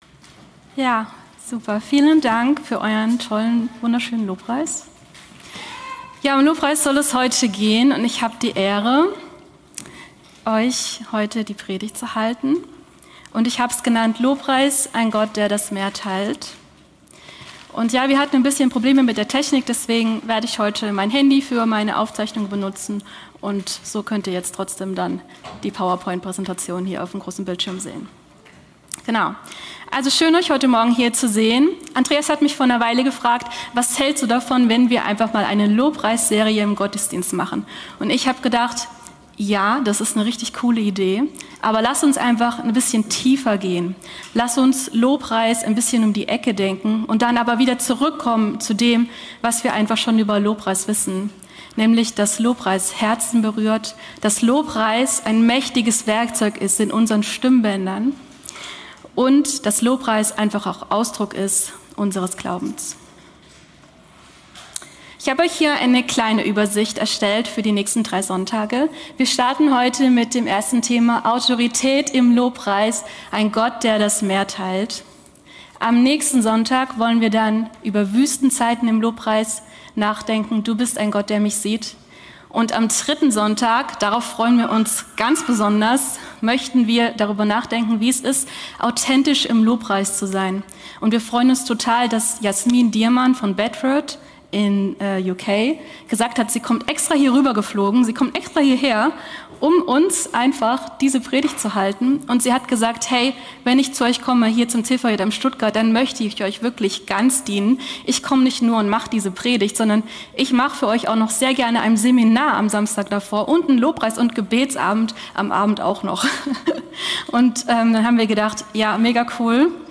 Teil 1 der Predigtreihe zum Thema. Lobpreis: Autorität im Lobpreis - Ein Gott der das Meer teilt